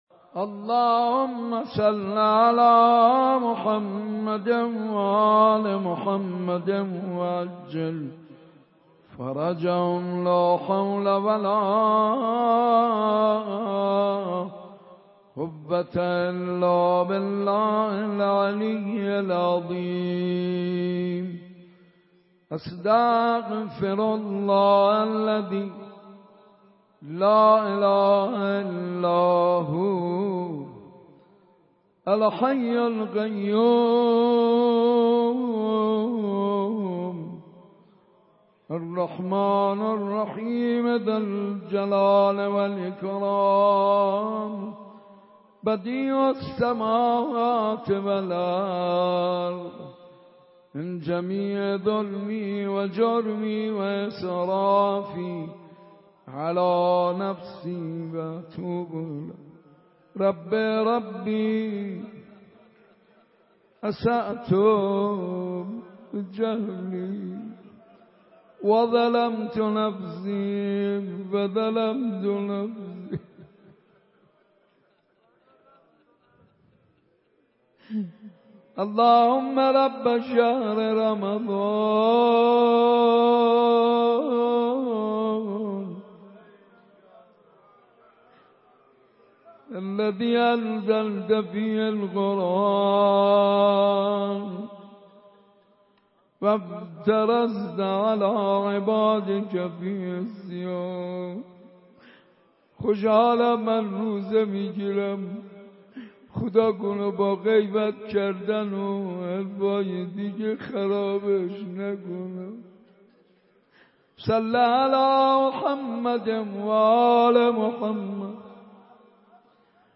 دعا و مناجات